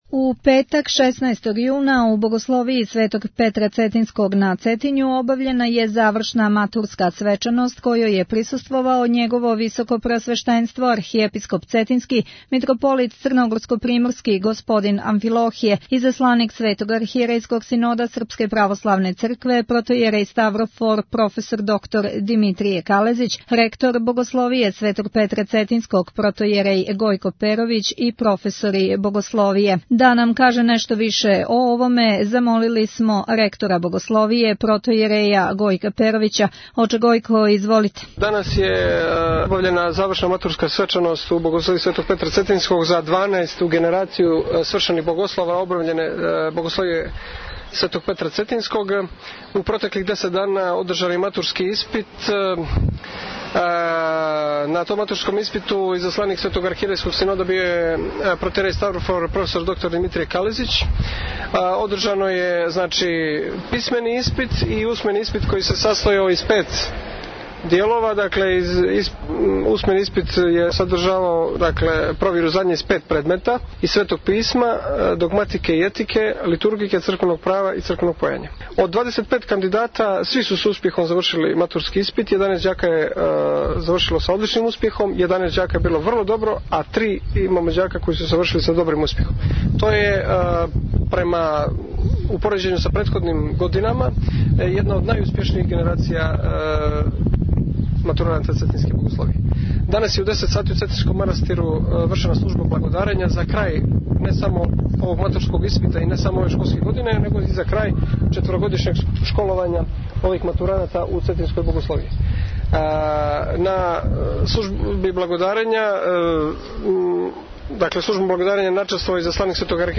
Запис са завршне матурске свечаности у Богословији Светог Петра Цетињског | Радио Светигора
Извјештаји